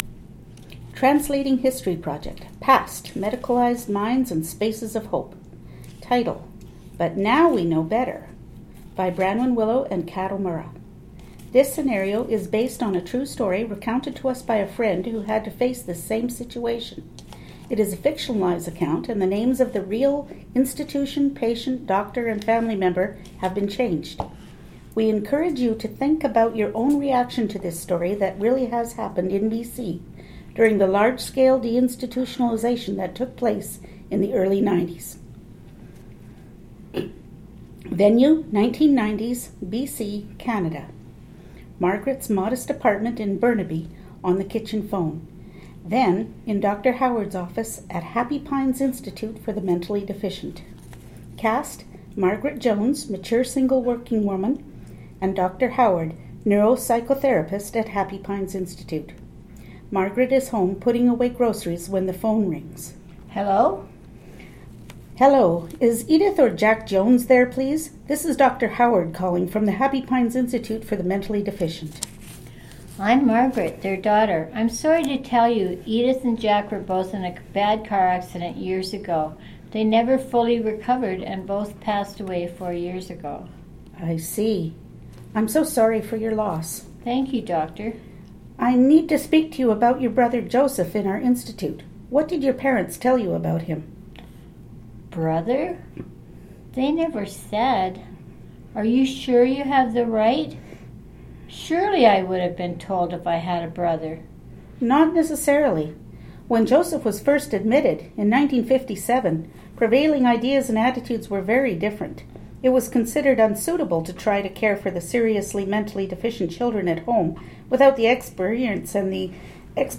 The components for this unit are three short plays, fictionalized accounts of mental health experiences in the past, present, and imagined future.